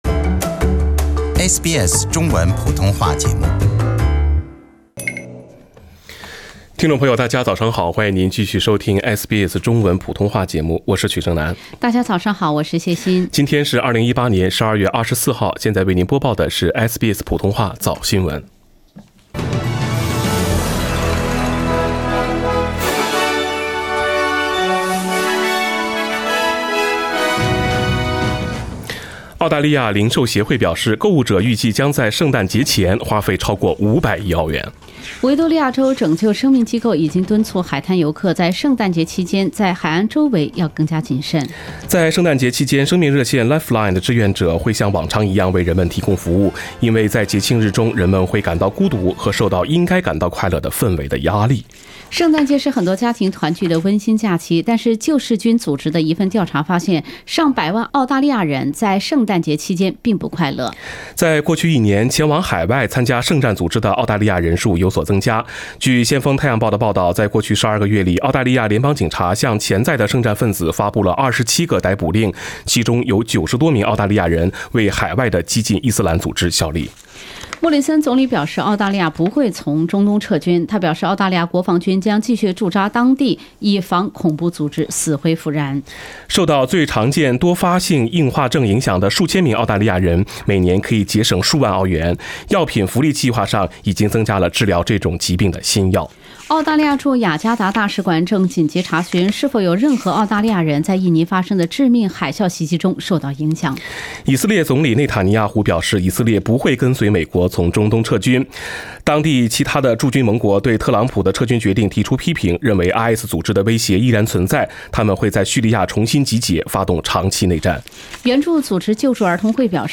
SBS 早新闻 （12月24日）